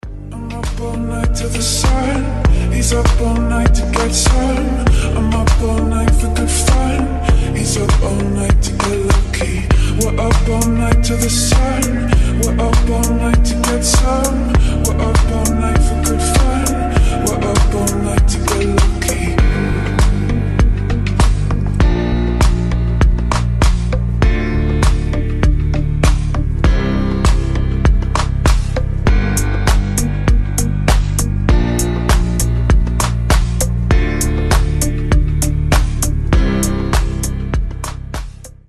Танцевальные рингтоны
Deep house , Мужской голос , Чувственные
Chill , Релакс